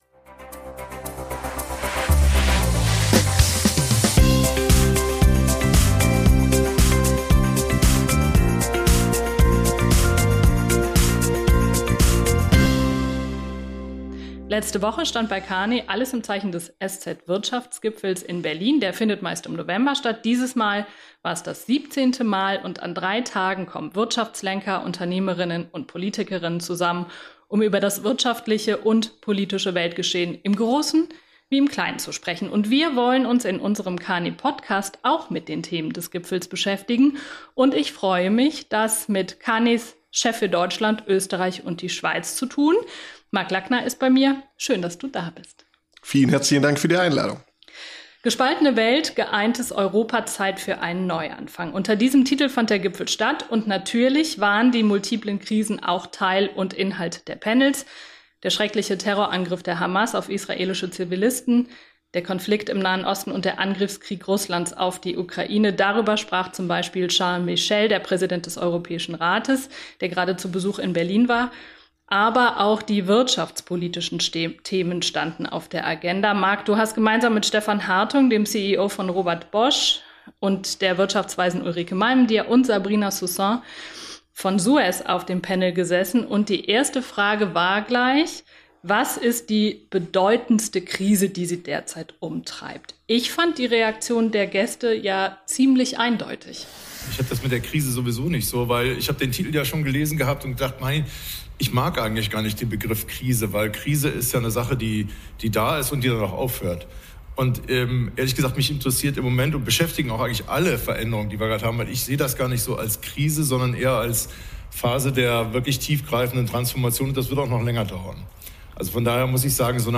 Die beiden werfen einen Blick zurück auf drei spannende Kongresstage – und auch einige der wichtigsten deutschen Unternehmenslenker kommen im Kearney-Podcast zu Wort.